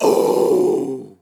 Metal Scream Sample
Categories: Vocals Tags: dry, english, fill, male, Metal, sample, Scream, Tension
TEN-vocal-fills-100BPM-A-2.wav